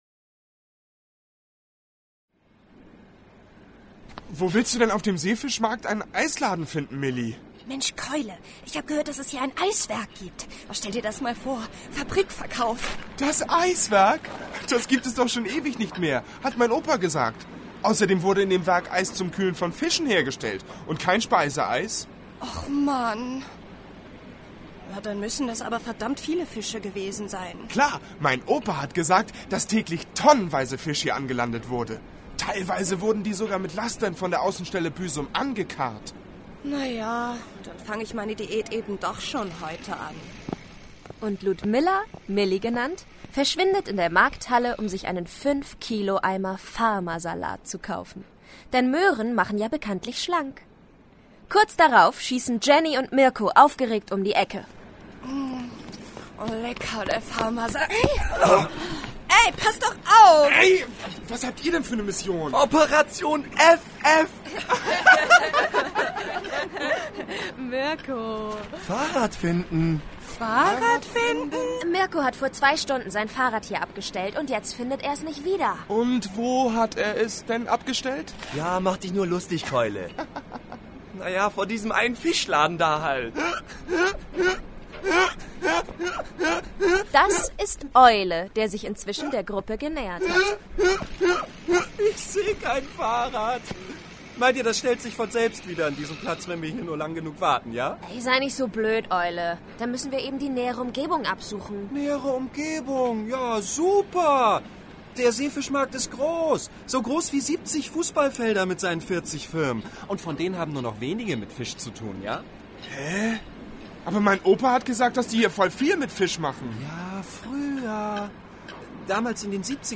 Hörspiele
Die Kurzhörspiele wurden von Studierenden der Schule für Schauspiel in der Landeshauptstadt Kiel sowie der Fachhochschule Kiel, Fachbereich Medien, produziert und sind auf den folgenden Seiten zu finden: